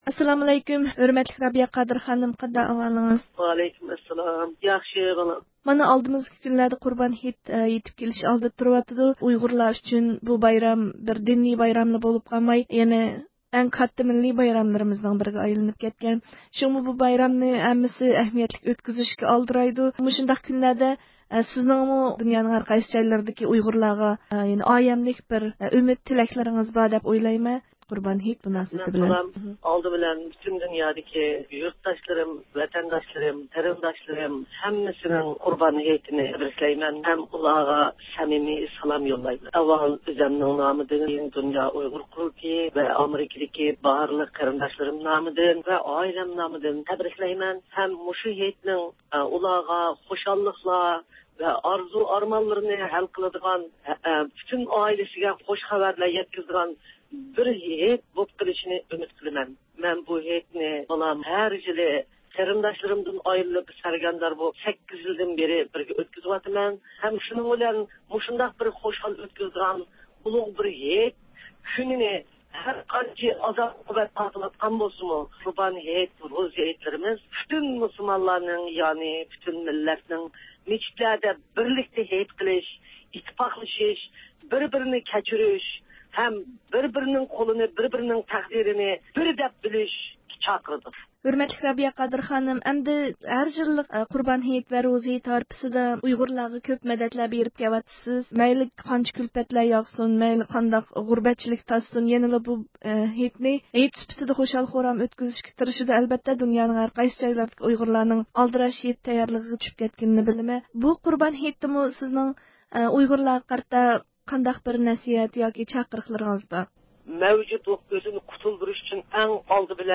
ﺋﯘﻳﻐﯘﺭ ﻣﯩﻠﻠﻰ ﻫﻪﺭﯨﻜﯩﺘﯩﻨﯩﯔ ﻟﯩﺪﯦﺮﻯ ﺩﯗﻧﻴﺎ ﺋﯘﻳﻐﯘﺭ ﻗﯘﺭﯗﻟﺘﯩﻴﻰ ﺭﻩﺋﯩﺴﻰ ﺭﺍﺑﯩﻴﻪ ﻗﺎﺩﯨﺮ ﺧﺎﻧﯩﻢ ﺳﯚﺯﺩﻩ.
ئۇيغۇر مىللىي ھەرىكىتى رەھبىرى رابىيە قادىر خانىم قۇربان ھېيت ھارپىسىدا ئالاھىدە زىيارىتىمىزنى قوبۇل قىلىپ، دۇنيانىڭ ھەر قايسى جايلىرىدىكى ئۇيغۇرلارغا ئايەملىك سالام يوللىدى ۋە بىرلىك ئىتتىپاقلىققا، بىر بىرىگە مەدەت ۋە ياردەمدە بولۇپ، دىنىي ۋە مىللىي بۇرچىنى ئادا قىلىشقا چاقىردى.